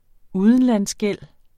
Udtale [ ˈuðənlans- ]